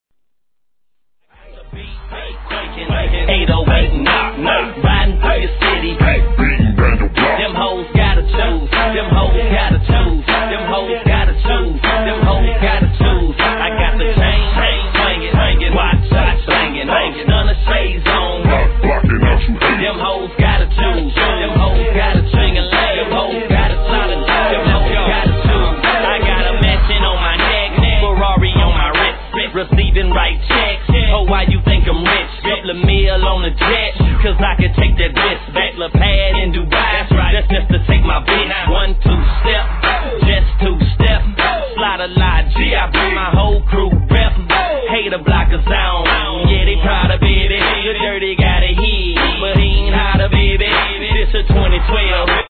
HIP HOP/R&B
(78 BPM)